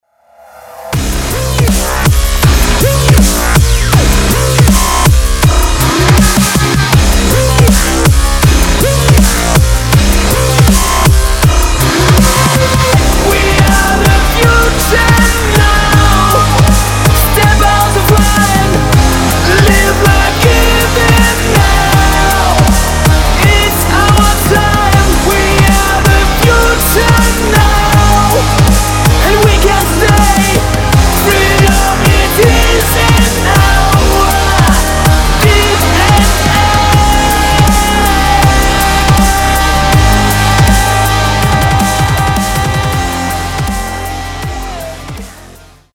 • Качество: 320, Stereo
мужской вокал
громкие
жесткие
мощные
Драйвовые
мелодичные
взрывные
Electronic Rock
Драйвовый электронный рок с элементами дабстепа